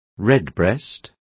Also find out how petirrojo is pronounced correctly.